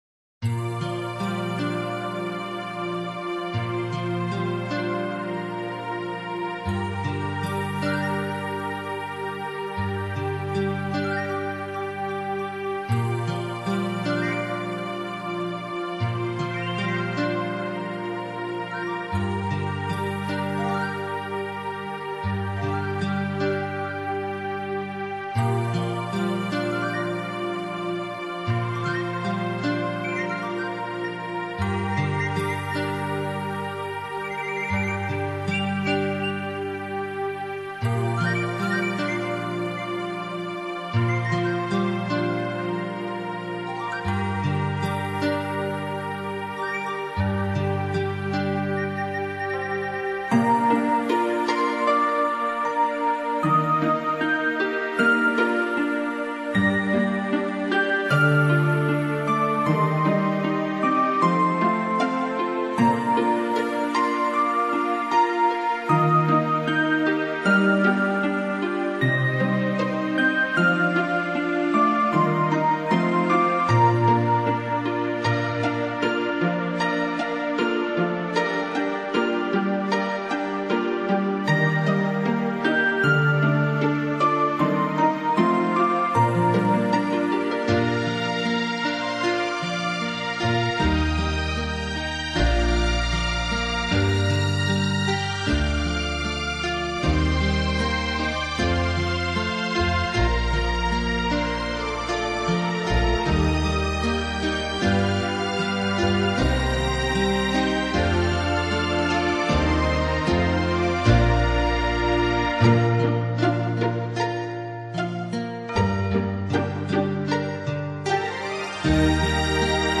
維持一貫的淡雅風格，音樂表現愈趨醇熟，